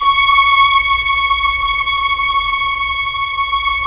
Index of /90_sSampleCDs/Roland LCDP13 String Sections/STR_Symphonic/STR_Symph. %wh